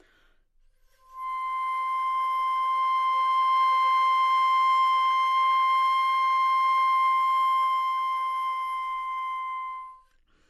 长笛单音（吹得不好） " 长笛C6坏动态
描述：在巴塞罗那Universitat Pompeu Fabra音乐技术集团的goodsounds.org项目的背景下录制。
Tag: 好声音 C6 单注 多重采样 纽曼和U87 长笛